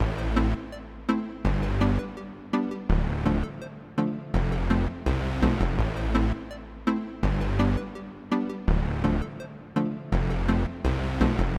雷盖顿的旋律
Tag: 83 bpm Reggaeton Loops Synth Loops 1.95 MB wav Key : A